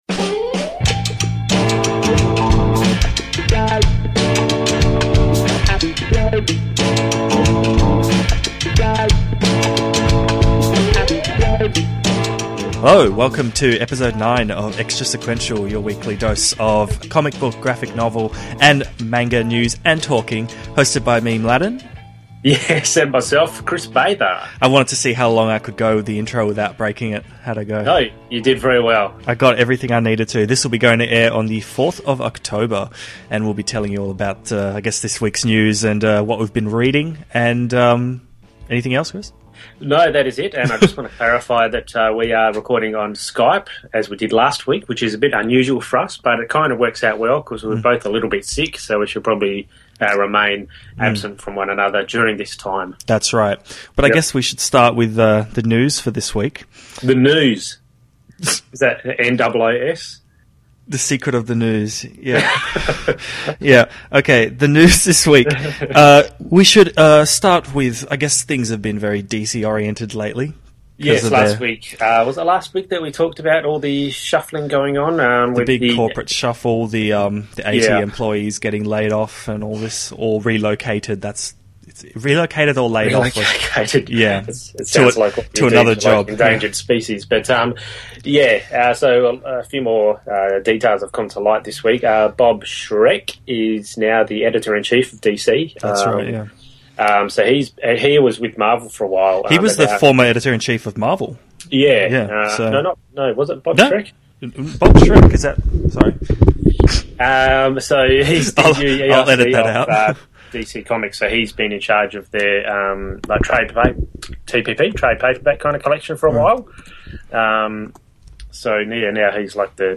Hear two Aussies talking about American and French comics, films and general pop culture-ness. Also, besides the topics below we talk about the future of Star Wars films in 3D, Captain Kirk’s alien romantic tendencies, Shrek’s snarkiness and more.